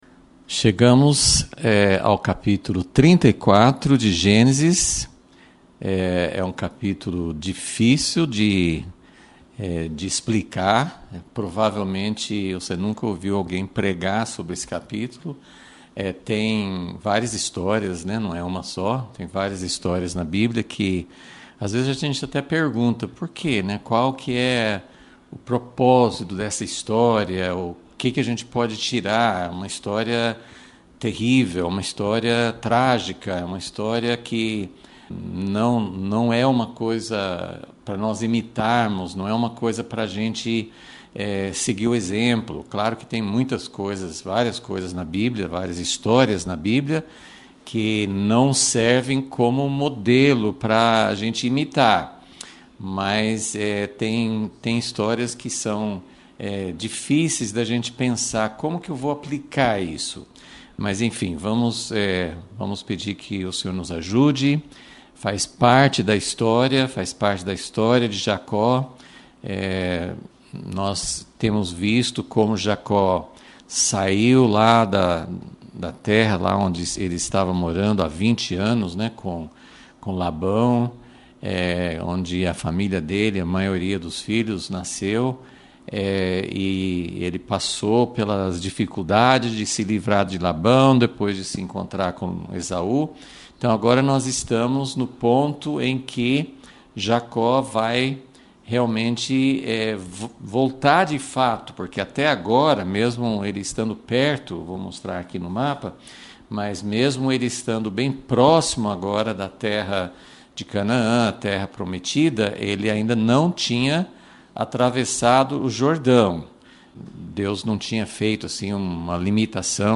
Aula 97 – Gênesis – Uma história de abuso e vingança